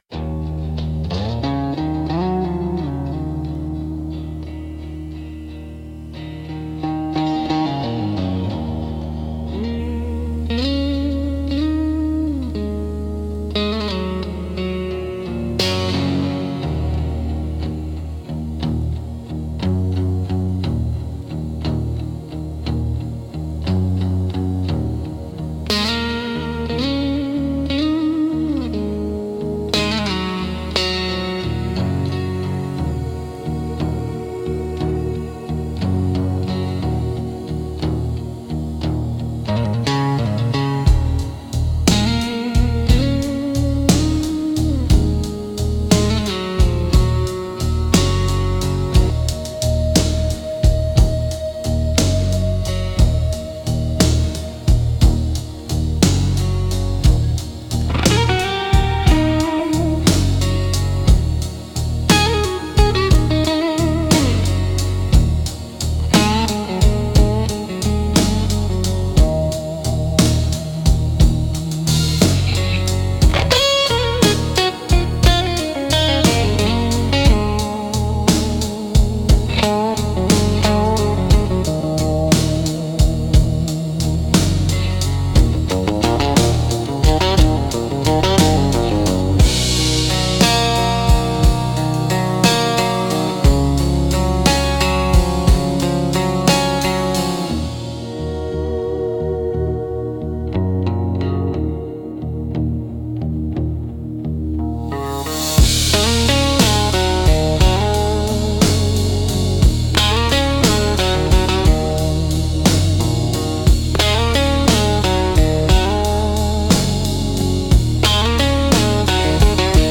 Instrumental - Phantom Freight 3.53